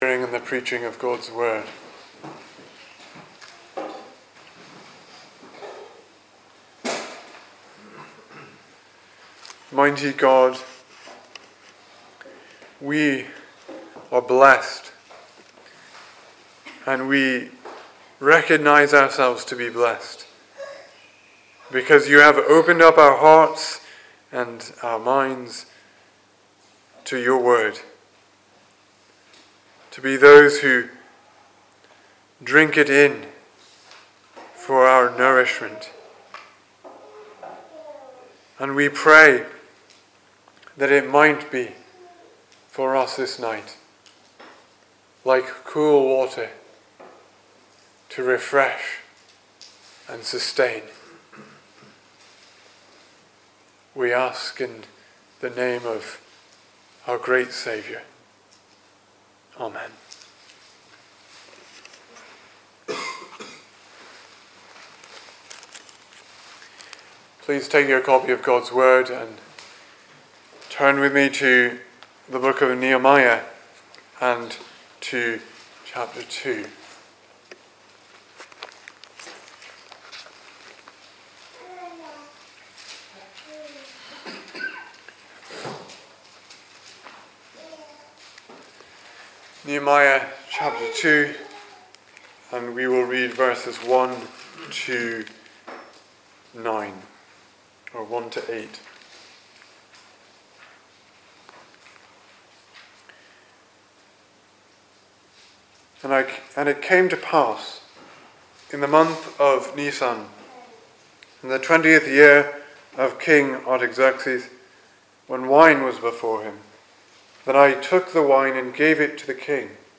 2019 Service Type: Sunday Evening Speaker